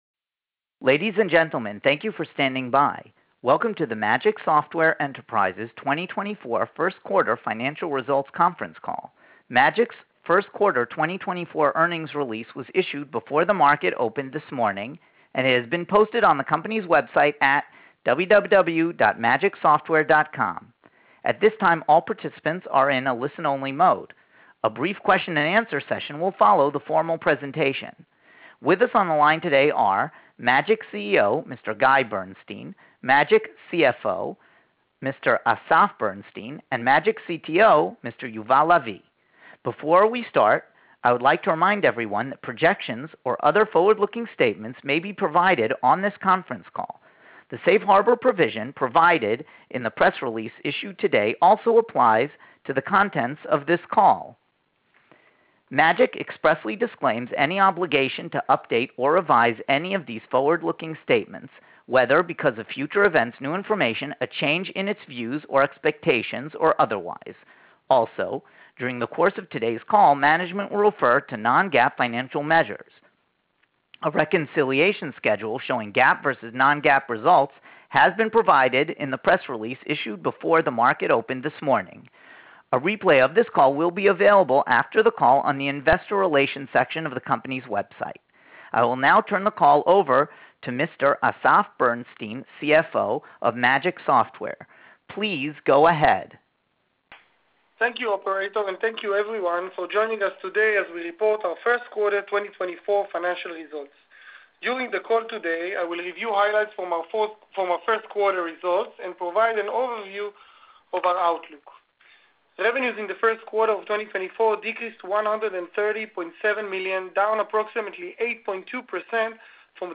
Quarter #1: Press Release | Conference Call (MP3)